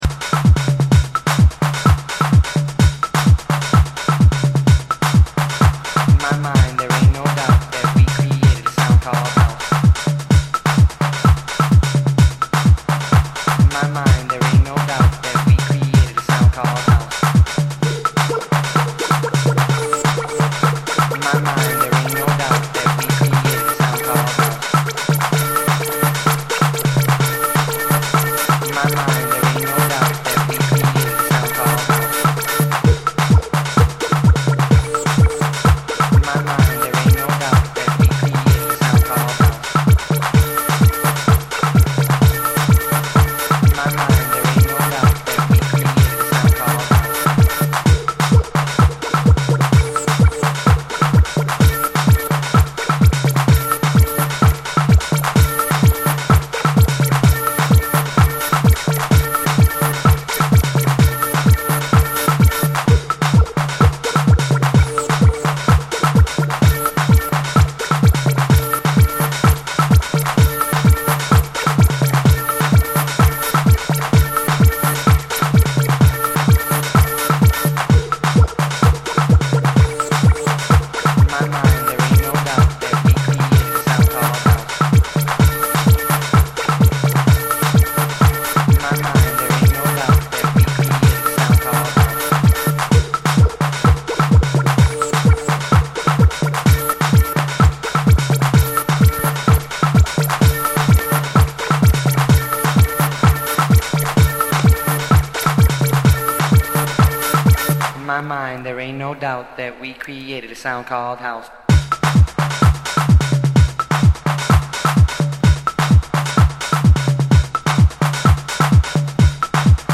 a true US House legend though somehow criminally underrated.